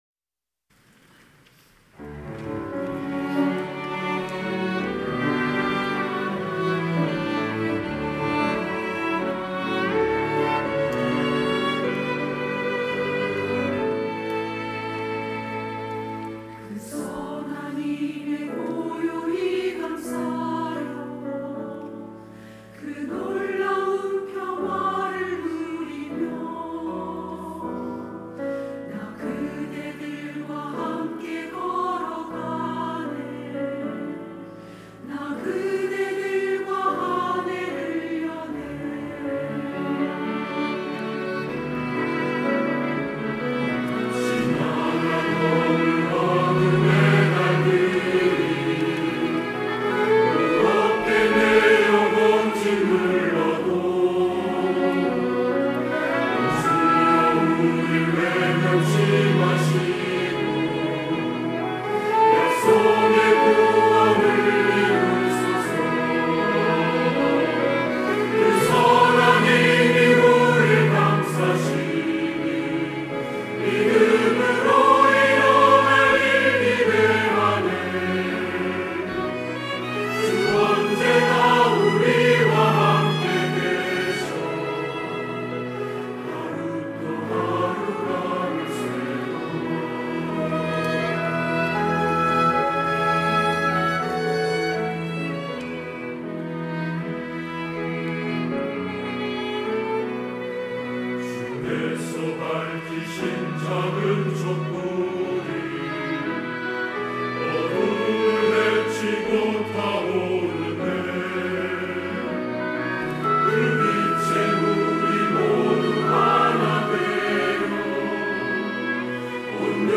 할렐루야(주일2부) - 선한 능력으로
찬양대